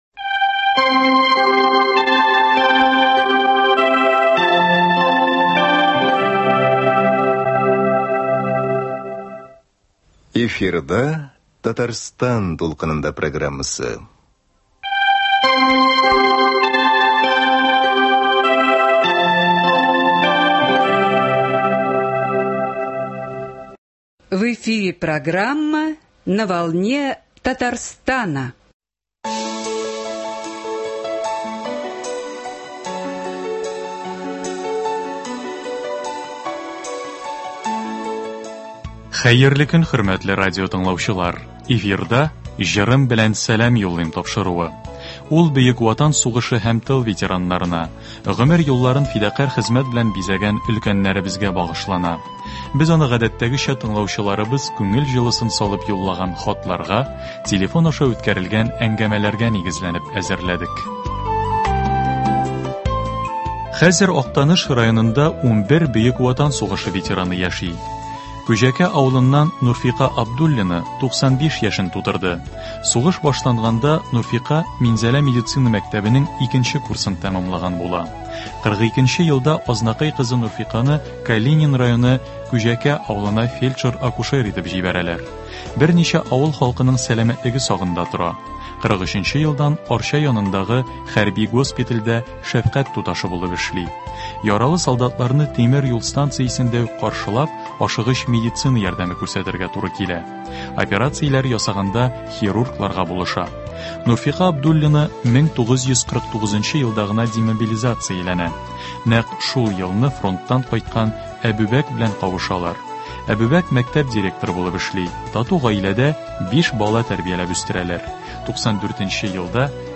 Хатлар буенча әзерләнгән “Җырым белән сәлам юллыйм” музыкаль программасы.